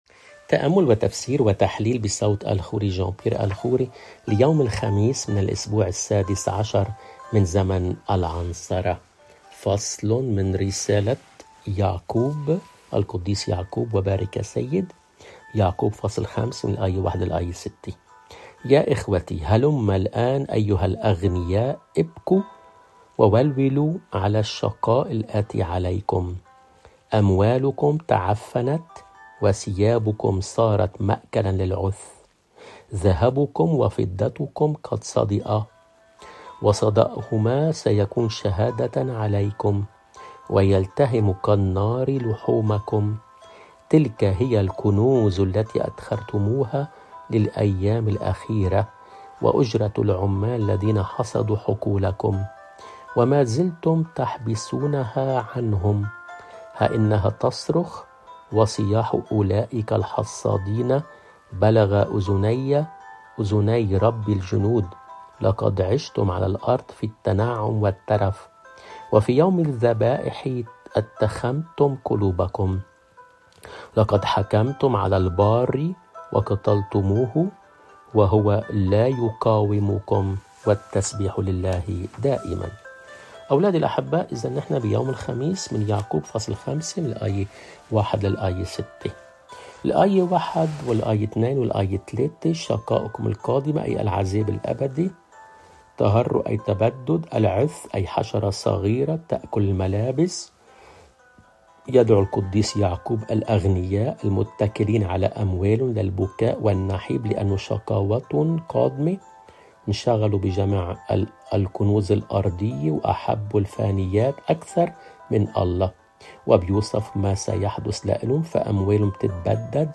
الرسالة والإنجيل